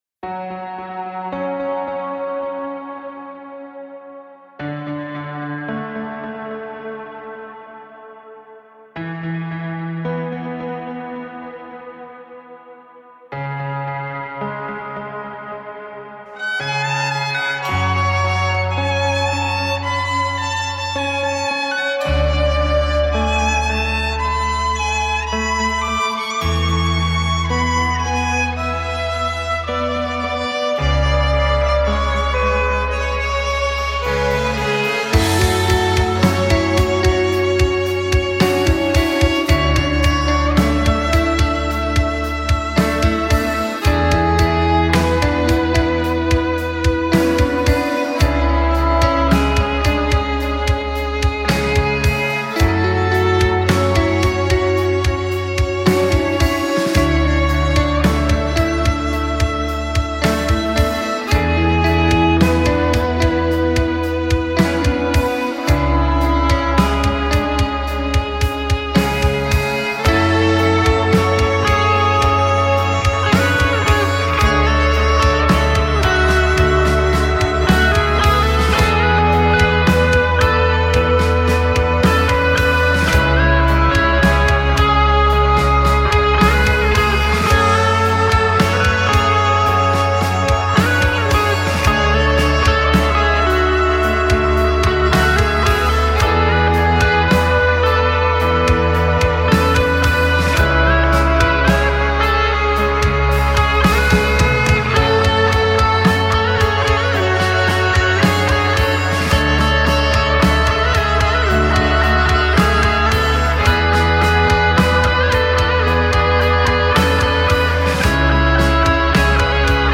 آهنگ بیکلام خارجی پست راک
Post rock